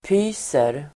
Uttal: [p'y:ser]